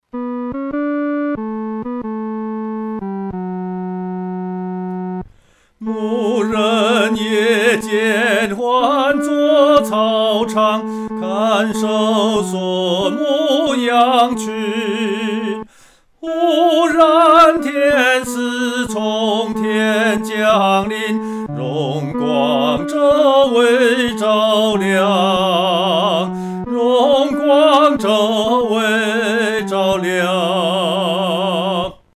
独唱（第三声）
牧人闻信-独唱（第三声）.mp3